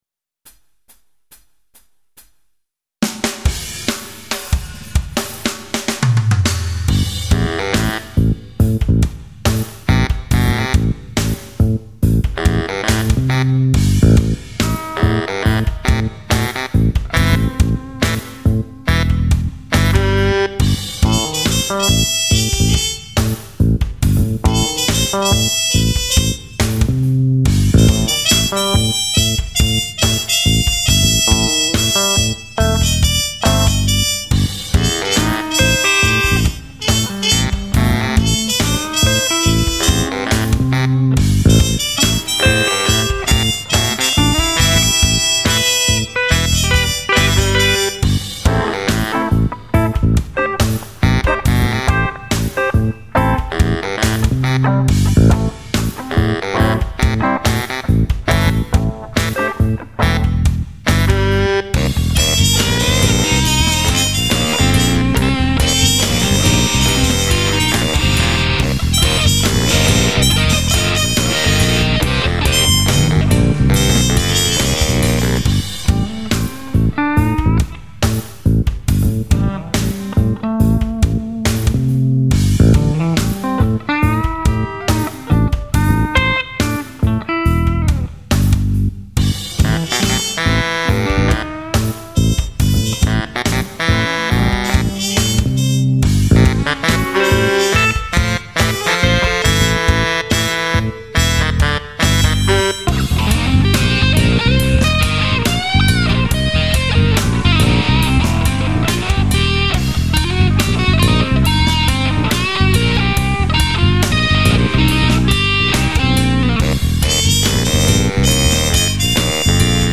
Die Solo-Gitarrenspuren sind mit dem Mittelfinger der rechten Hand gezupft, weil das zu dem Zeitpunkt, das einzige war, was ich am rechten Arm noch einigermassen kontrollieren konnte.